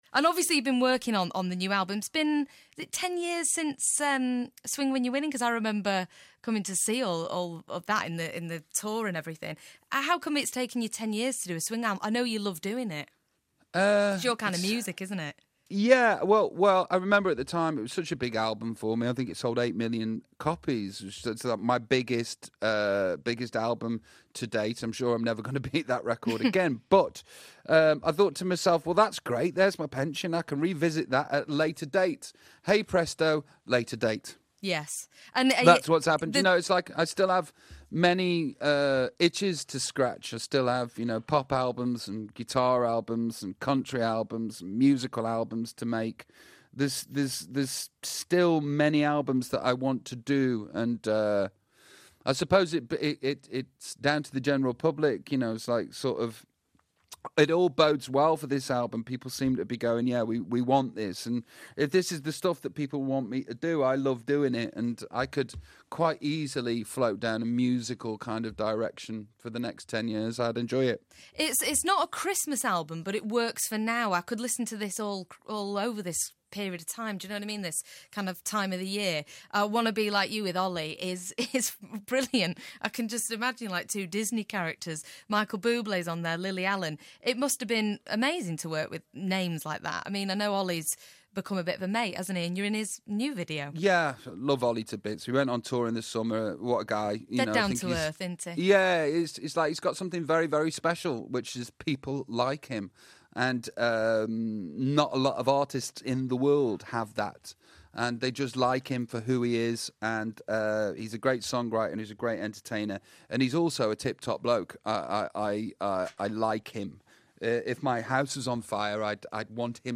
Hear the third part of Robbie Williams' chat